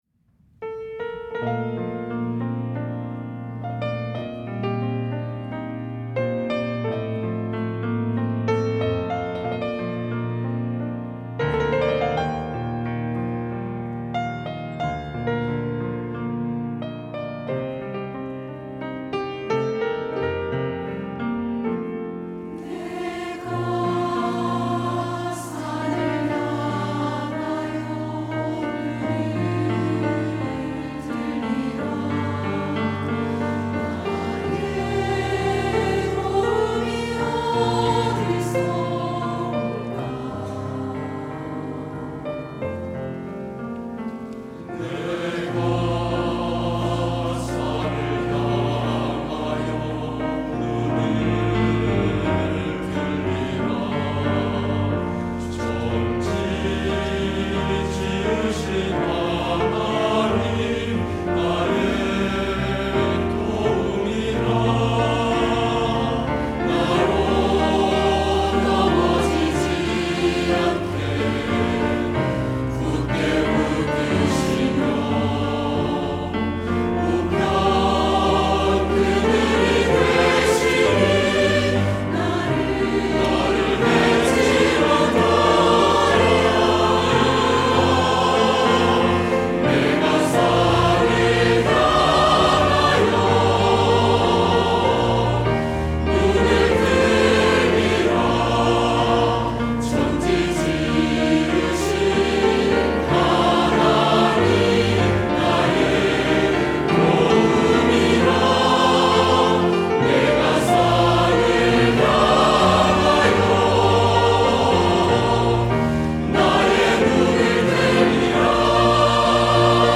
할렐루야(주일2부) - 내가 산을 향하여 눈을 들리라
찬양대